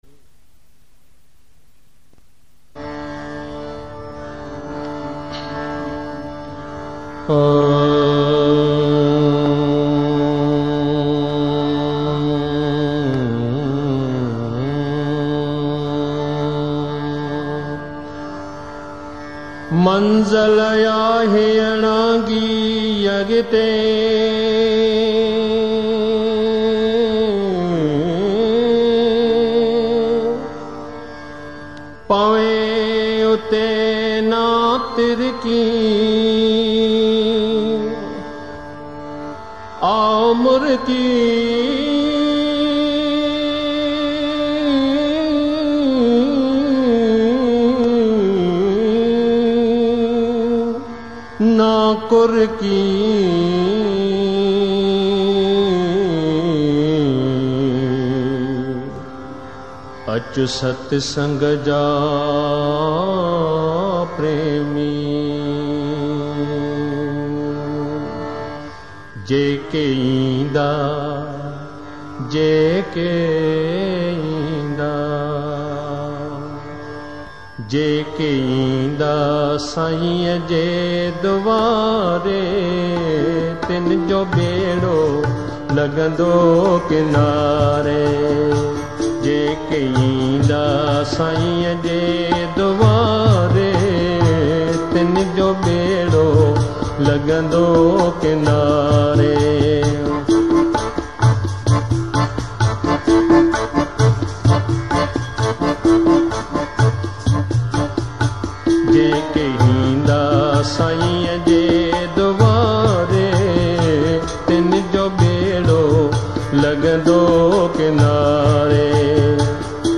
Classical Spiritual Sindhi Songs